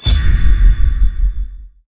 SFX item_armor_break.wav